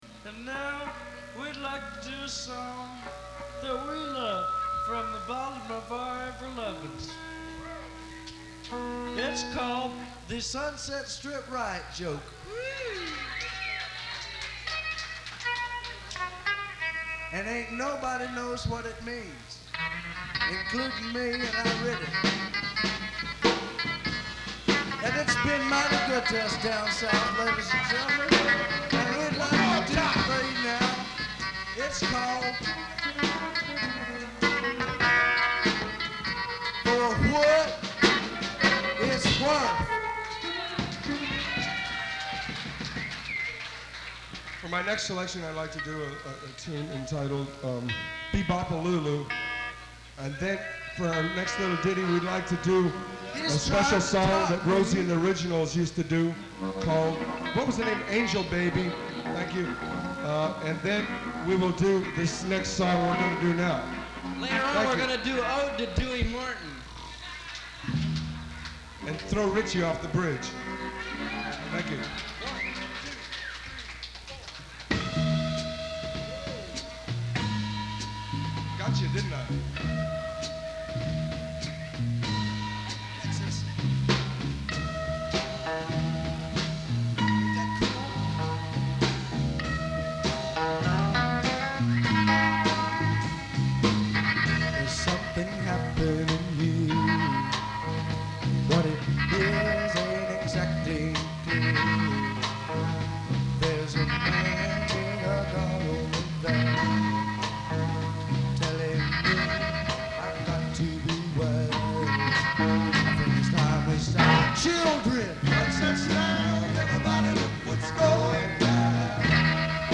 Además, está comprimido en MP3 pero… es lo que hay.